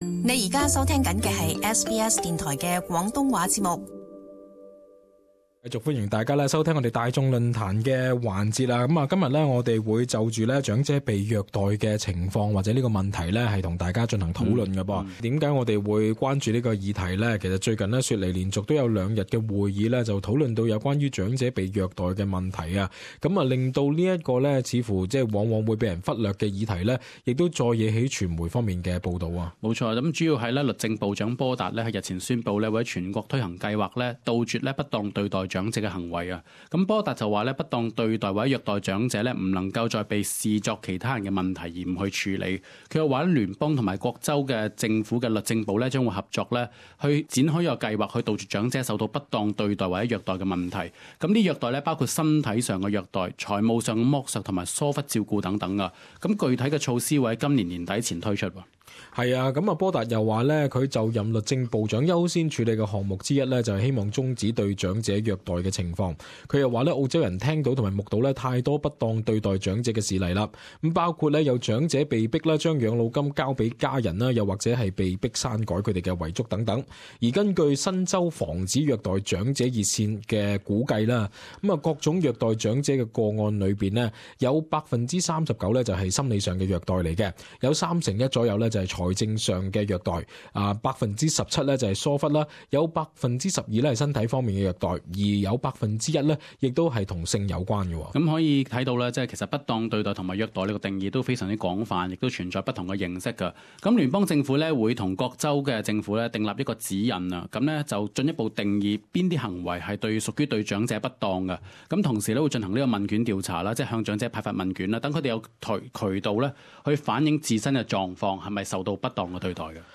Talkback: Elder Abuse